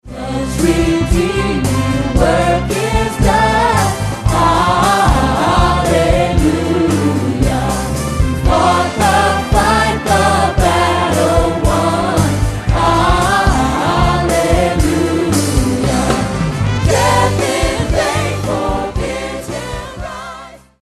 Favorite Easter Hymn
ChristTheLordIsRisenToday_ORGREC_samples.MP3